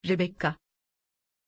Pronunția numelui Rebekka
pronunciation-rebekka-tr.mp3